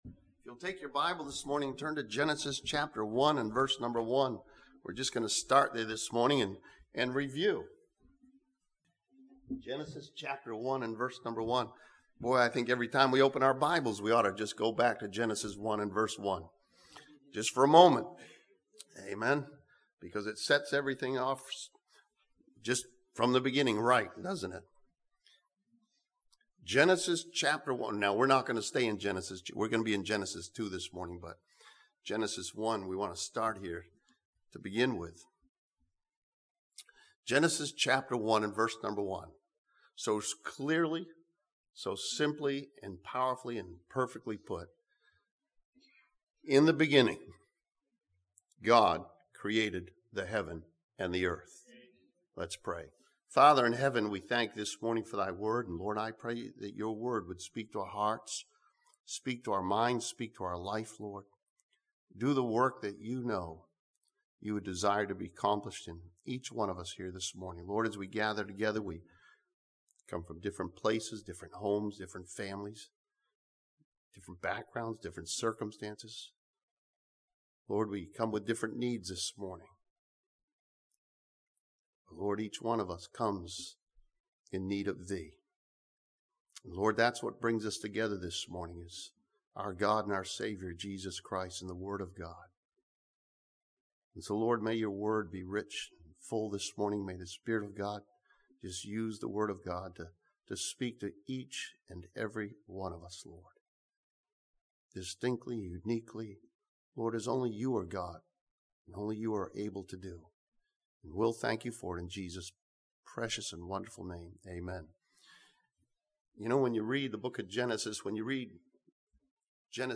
This sermon from Genesis chapter 2 studies the creation of Adam and learns how we can begin living for God.